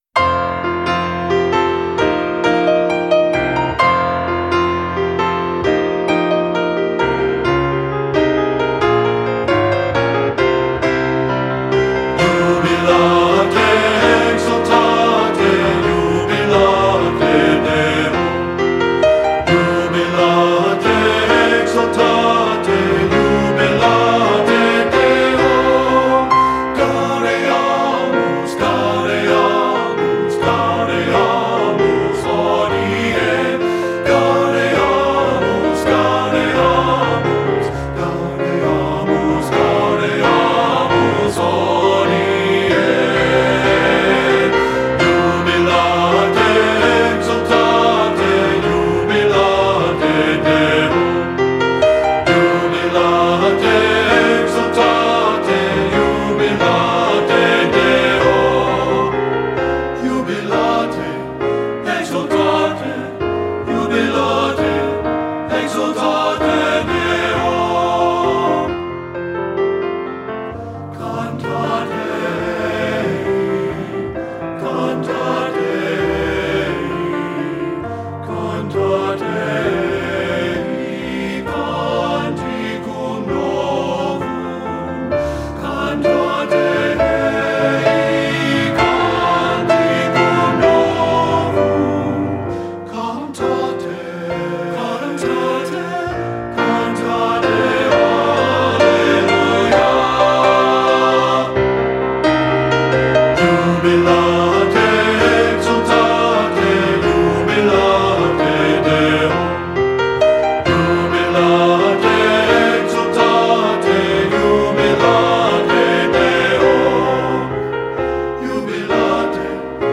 Voicing: TBB and Piano